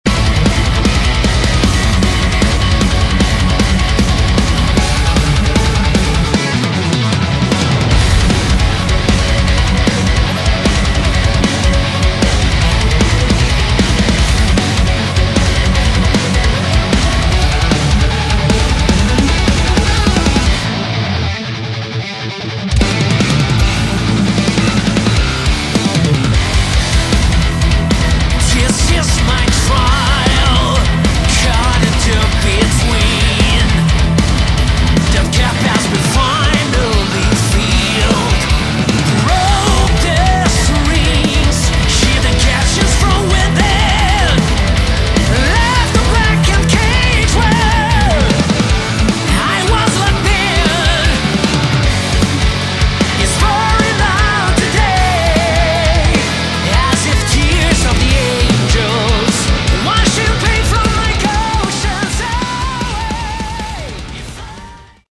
Category: Progressive Rock/Metal
lead vocals
guitars
bass
keyboards
drums